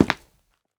Step3.ogg